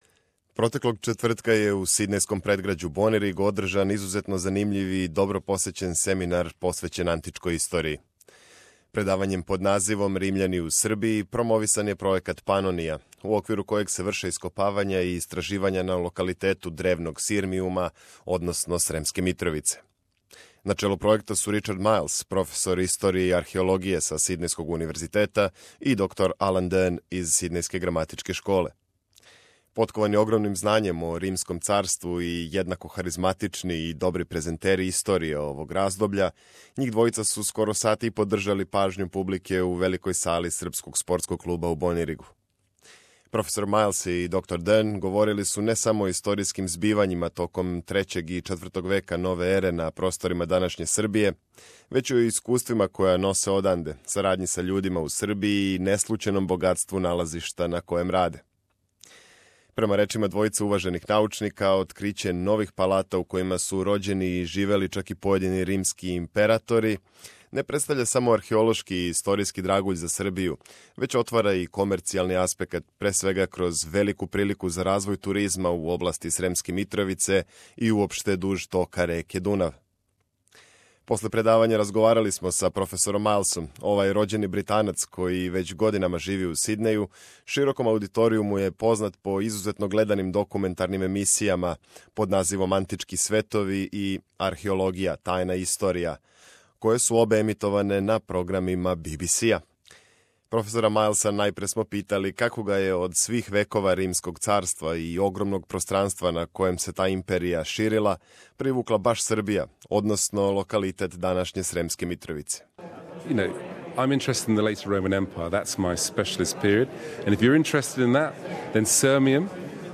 Интервју са професором Ричардом Мајлсом о Панонија пројекту и богатом археолошком наслеђу древне историје у Србији. На простору на којем се данас простире Србија рођено је 18 римских императора, а таквим бројем не може да се похвали ниједна друга земља осим Италије, каже познати британски археолог који већ годинама предаје на Универзитету у Сиднеју.